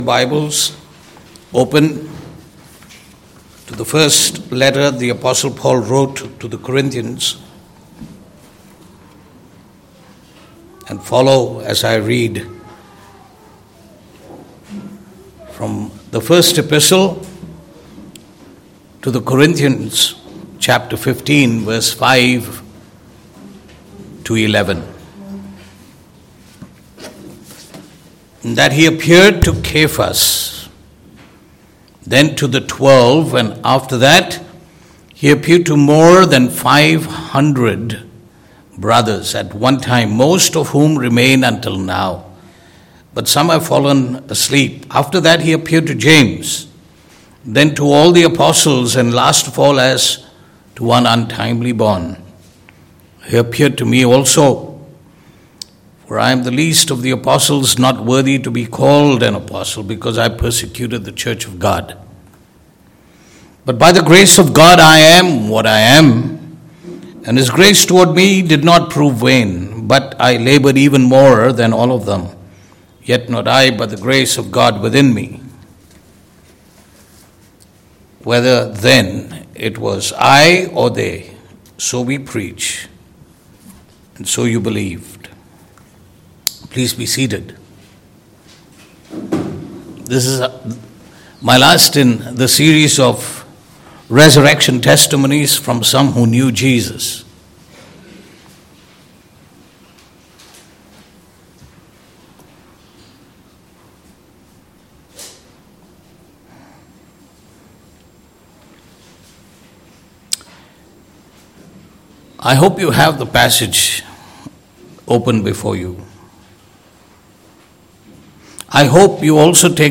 Passage: 1 Corinthians 15:5-11 Service Type: Sunday Morning « Resurrection Testimonies From Some Who Knew Jesus
Sermon-26Apr.mp3